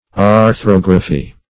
Arthrography \Ar*throg"ra*phy\, n. [Gr.